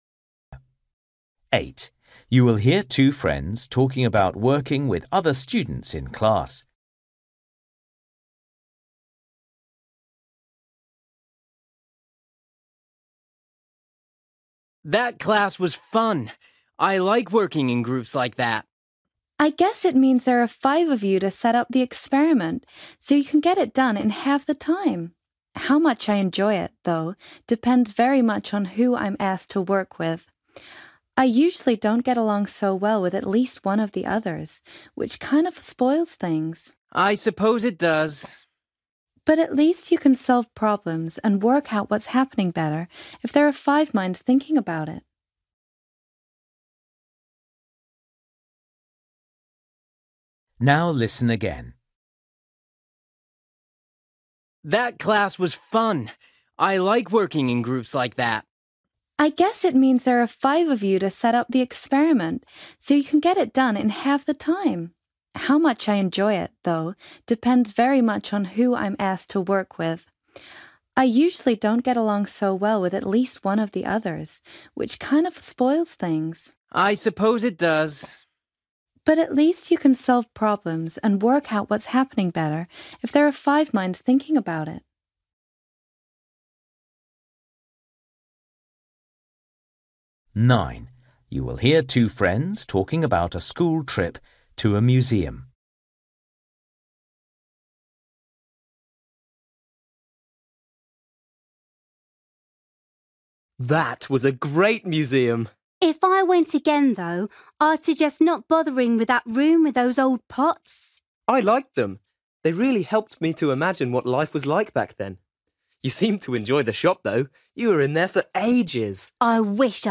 Listening: everyday short conversations
8   You will hear two friends talking about working with other students in class.
12   You will hear a boy asking a girl about an essay he has written.